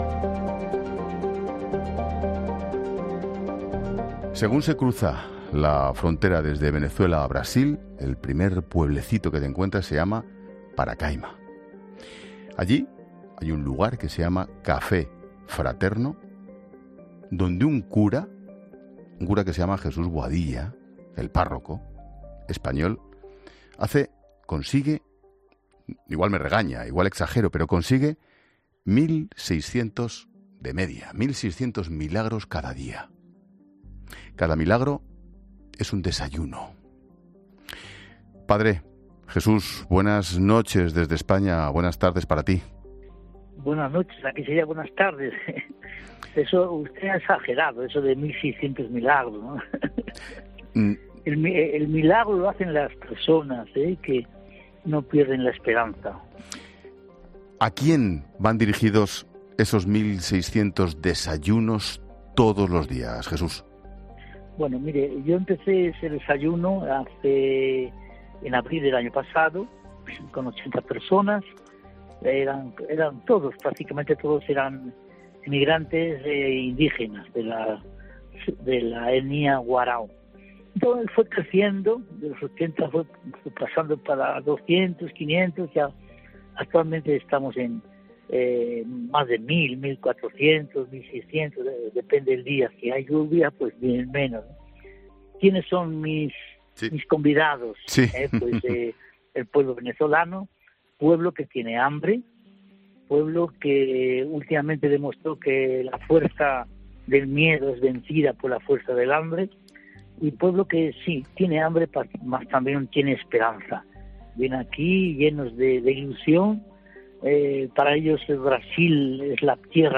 Ángel Expósito entrevista